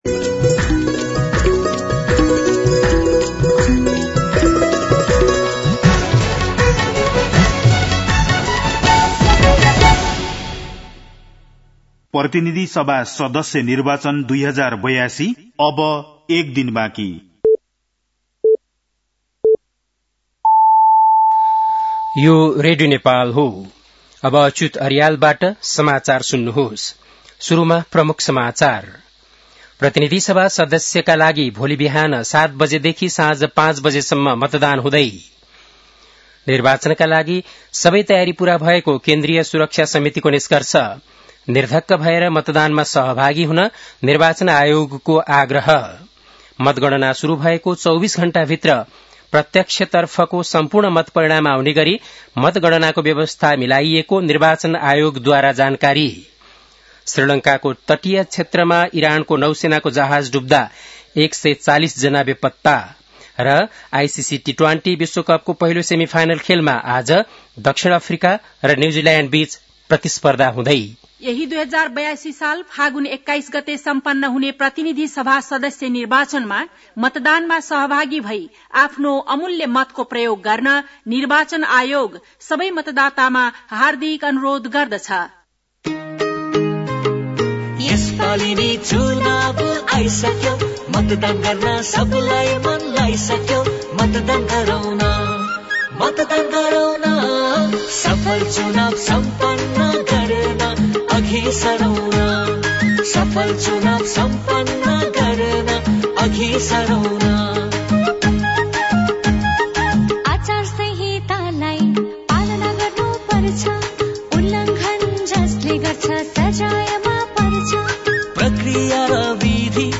बेलुकी ७ बजेको नेपाली समाचार : २० फागुन , २०८२
7-pm-news-11-20.mp3